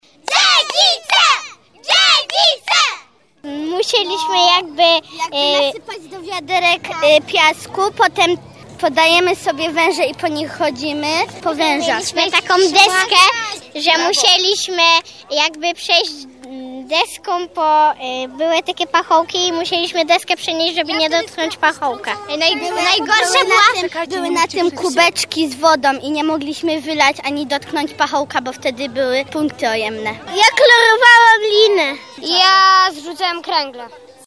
Memoriał Haliny Dzidówny dziecięcych i młodzieżowych drużyn pożarniczych odbył się dziś w Czechowicach-Dziedzicach.
Rywalizacja była zacięta, a doping gorący.